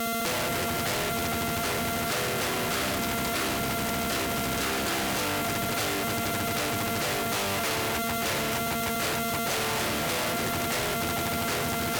плаг постоянно показывает ошибку , при попытке подать на него звук - космически зудит и свистит , не издавая больше никаких звуков...